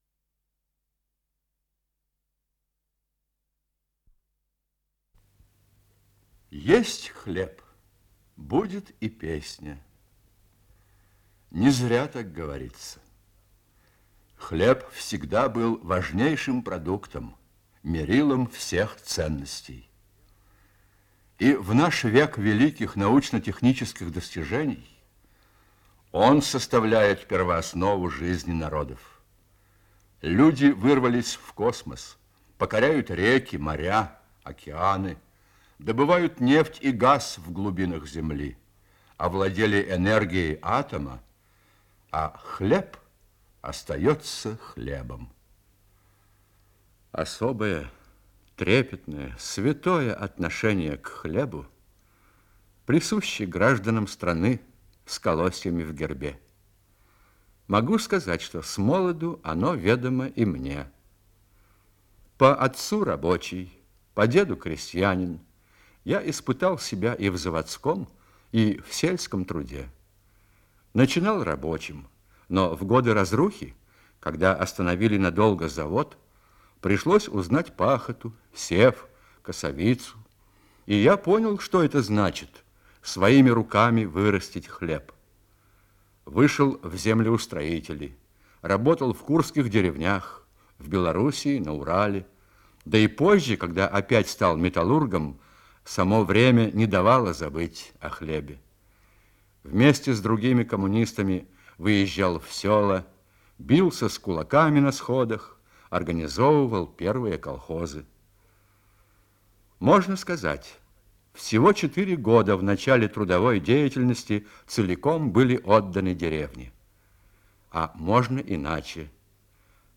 Исполнитель: Юрий Каюров - чтение